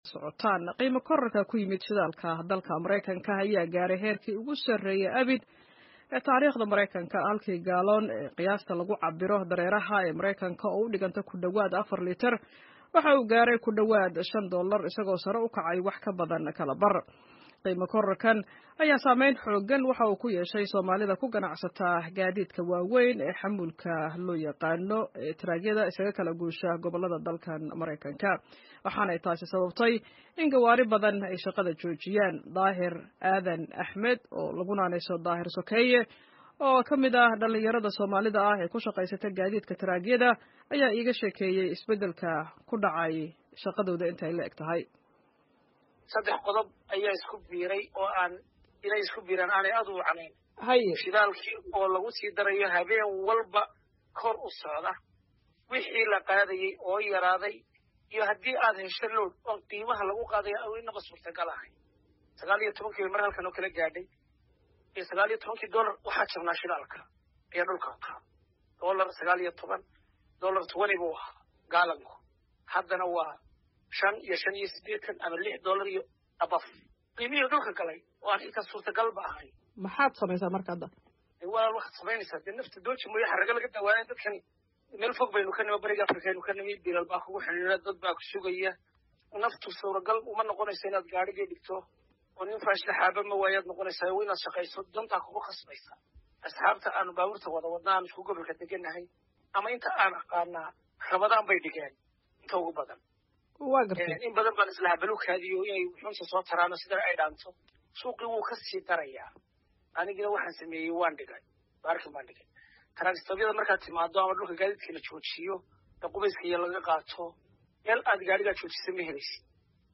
Wareysi: Gaadiidleyda Soomaalida ah ee Maraykanka oo ka cabanaya sare u kaca shidaalka